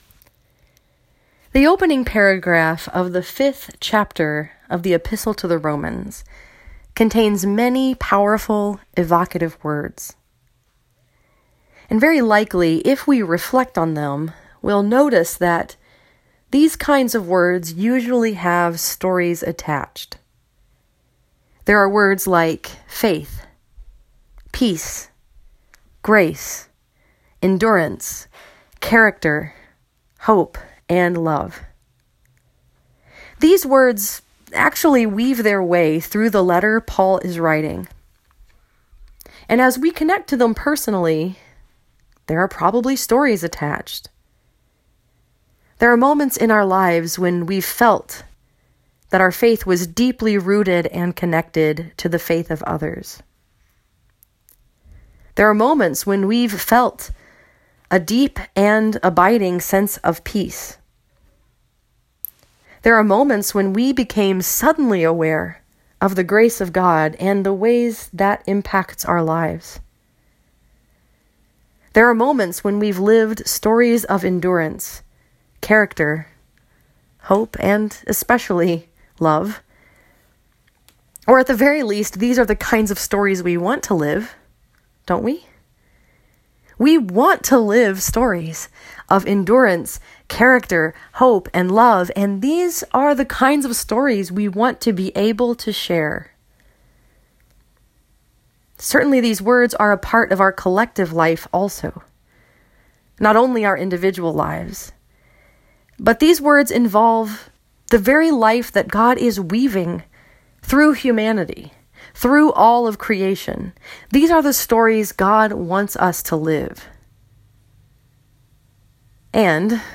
This sermon was preached at Allen Park Presbyterian Church in Allen Park, Michigan and was focused upon Romans 5:1-5.